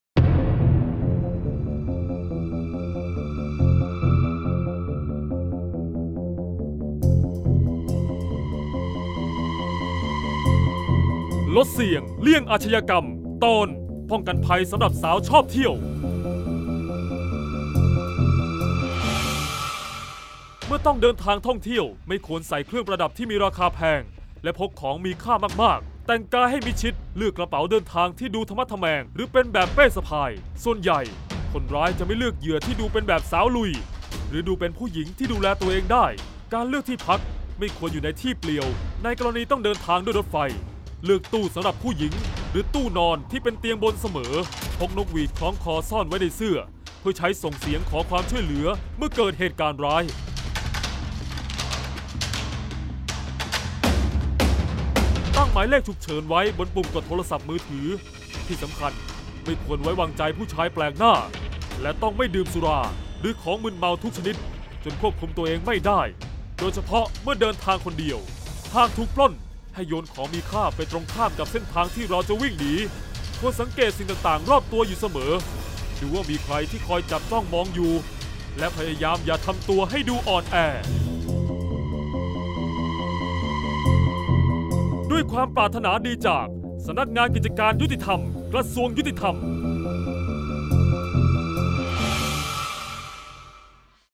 เสียงบรรยาย ลดเสี่ยงเลี่ยงอาชญากรรม 28-ป้องกันภัยสาวชอบเที่ยว